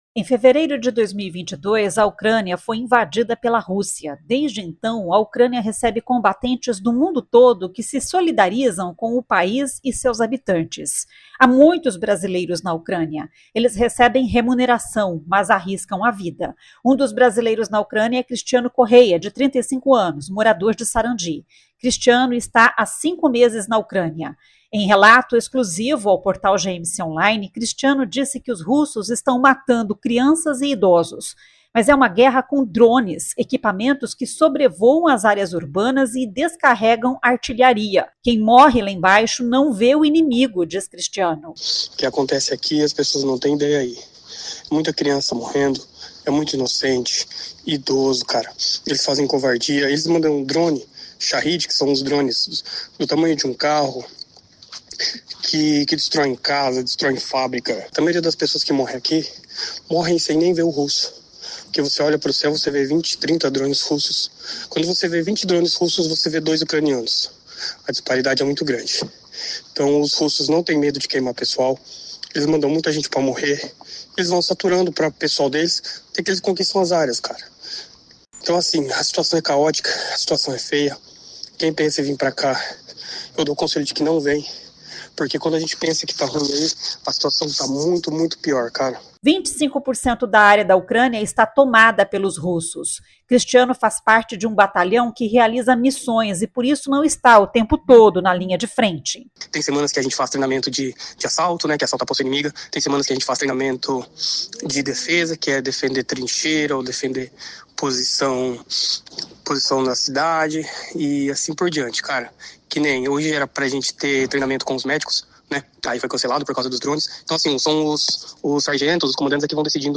Em entrevista exclusiva ao Portal GMC Online